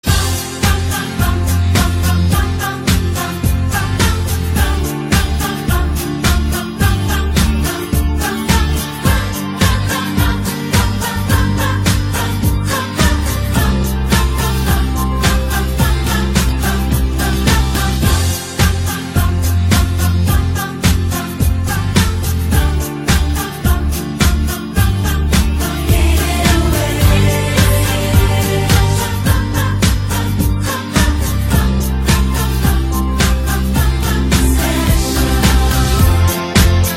• Качество: 128, Stereo
гитара
Cover
праздничные
хор
рождественские